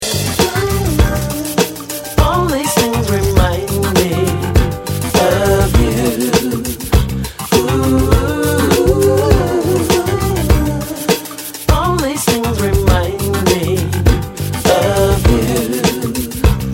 Lots of urban jungle style.